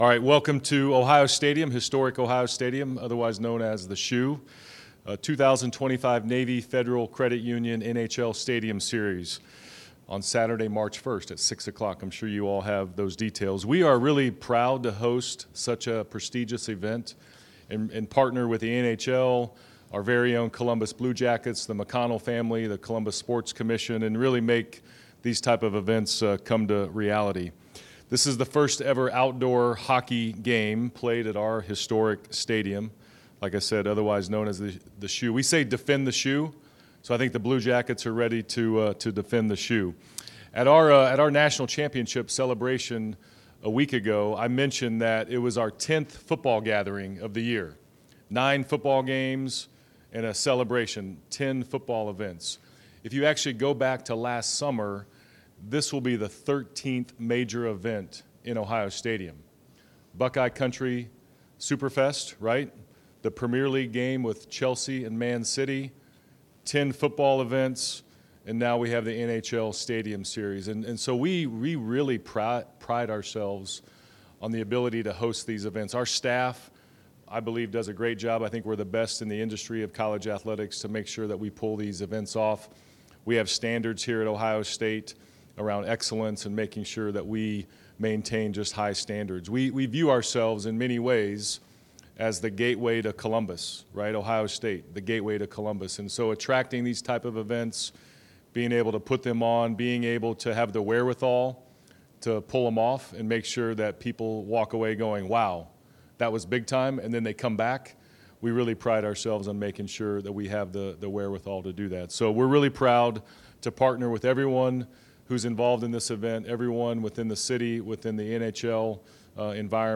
NHL Stadium Series Press Conference